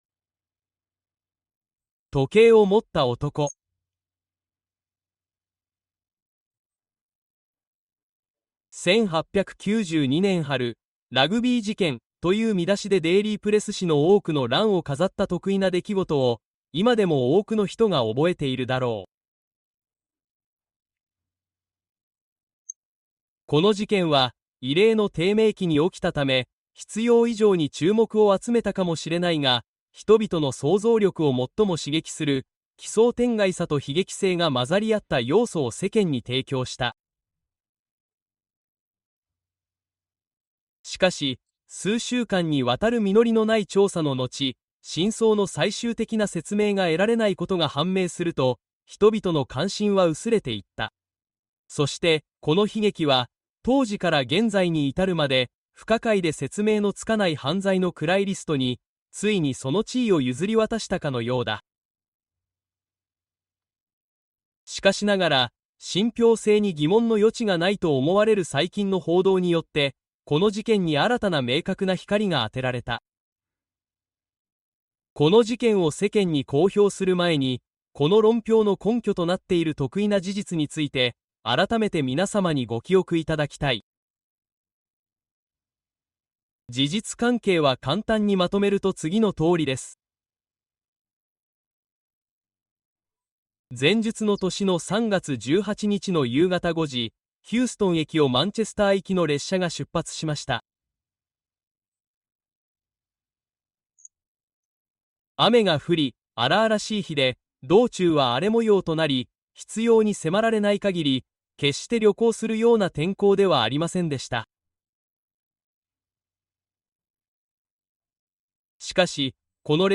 A Christmas Carol by Charles Dickens - Uplifting Full Audiobook